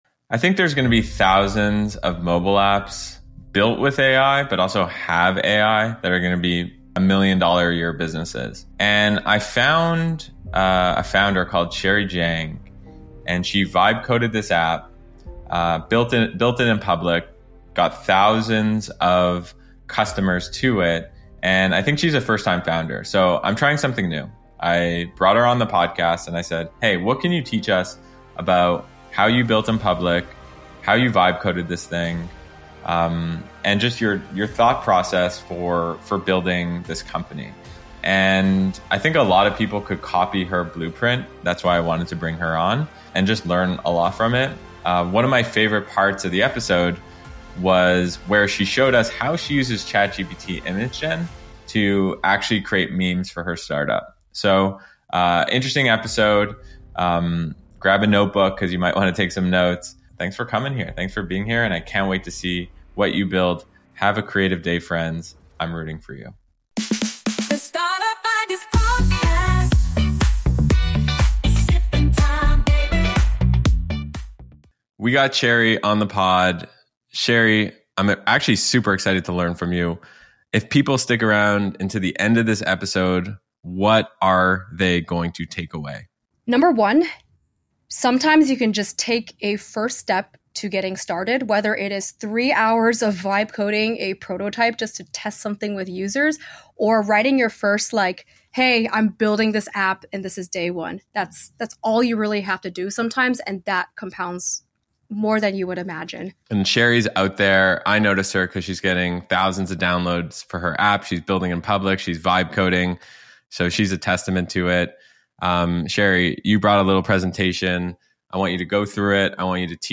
The conversation highlights how AI can be leveraged not just within products but also for marketing and content creation.